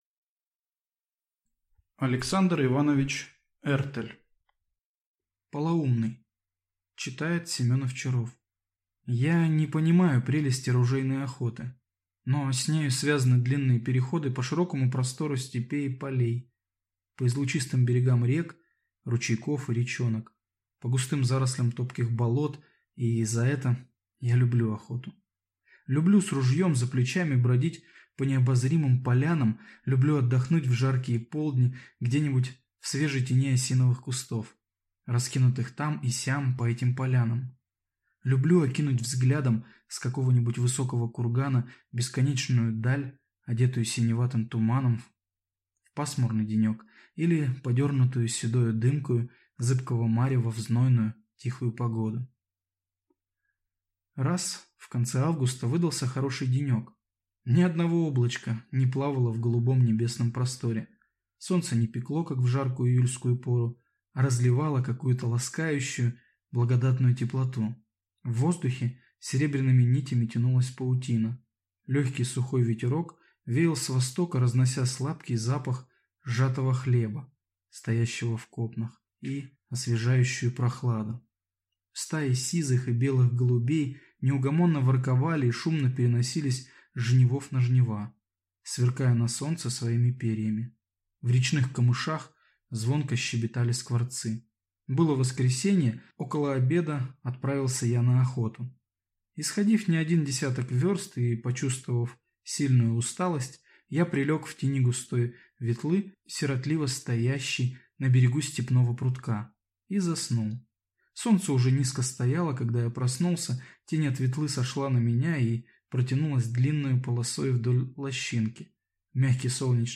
Аудиокнига Полоумный